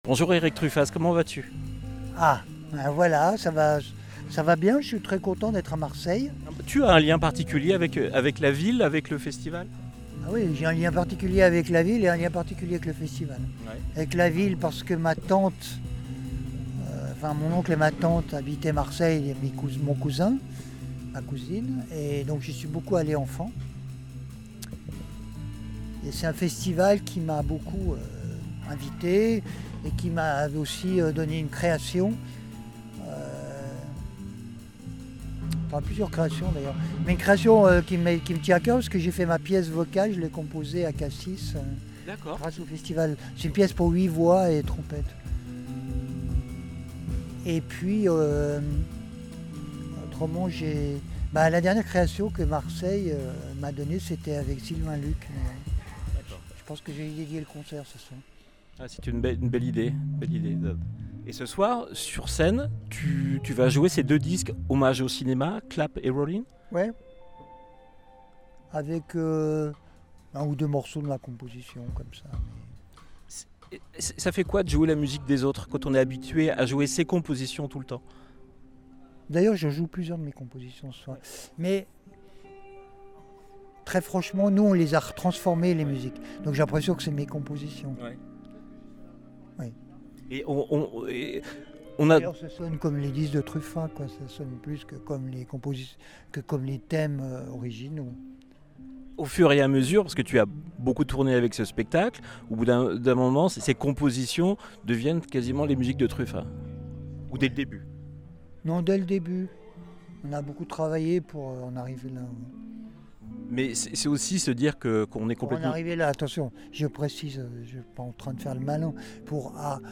erik-truffaz-podcast-marseille-36120.MP3